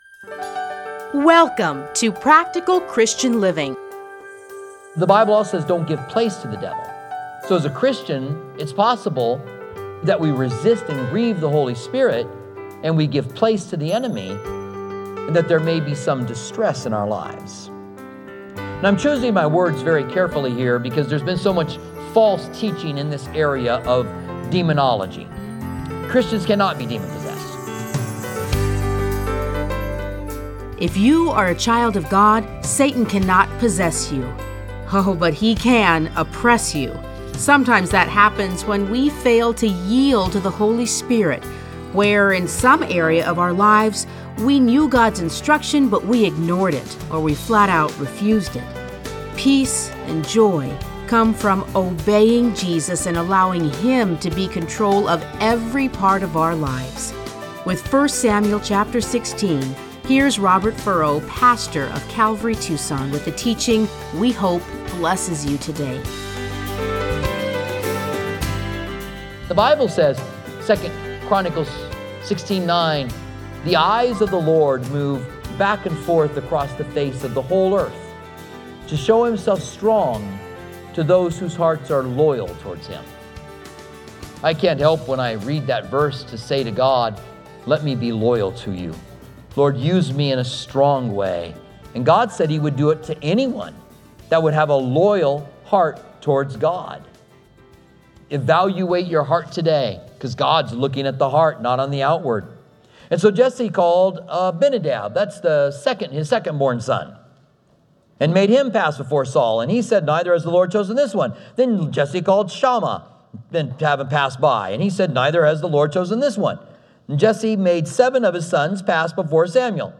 Listen to a teaching from 1 Samuel 16:1-23.